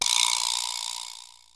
Percs
BUZZ_BAL.WAV